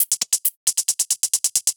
Index of /musicradar/ultimate-hihat-samples/135bpm
UHH_ElectroHatB_135-04.wav